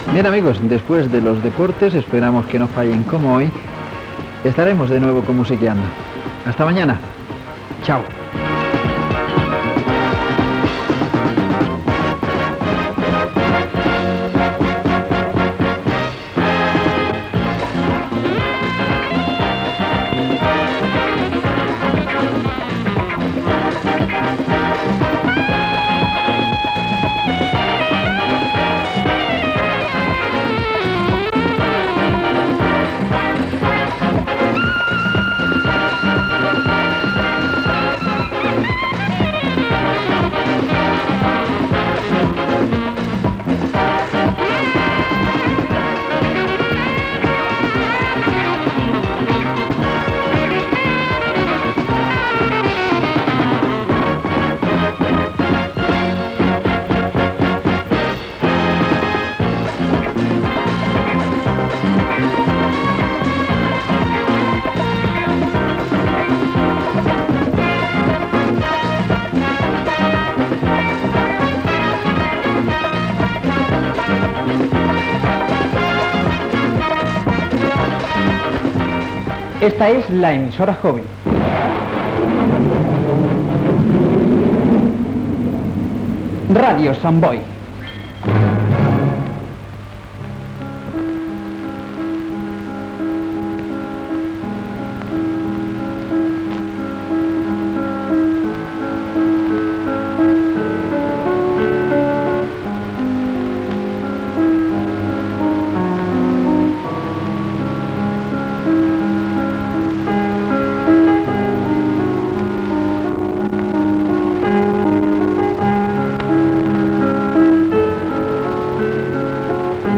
Comiat "Musiqueando", indicatiu de l'emissora, avanç de la programació del dia de demà, tancament d'emissió.
Musical
FM